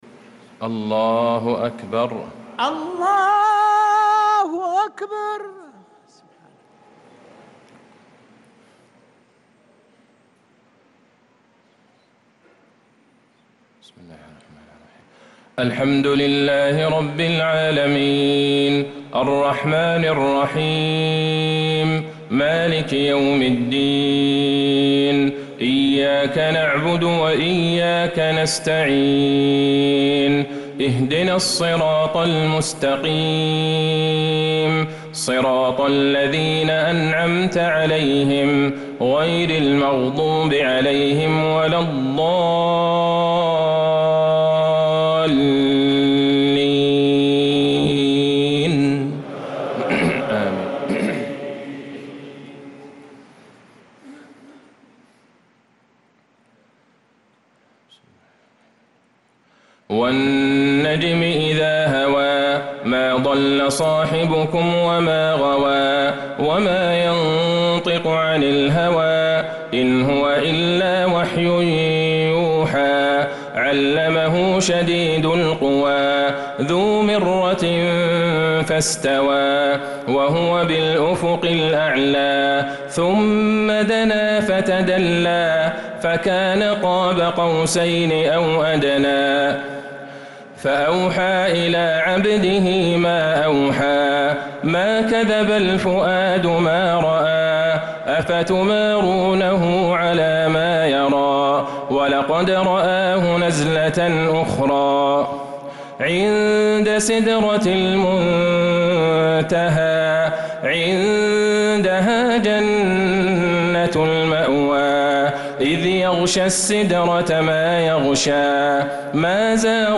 عشاء الخميس 2-3-1446هـ فواتح سورة النجم | Isha prayer from Surat An-Najm 5-9-2024 > 1446 🕌 > الفروض - تلاوات الحرمين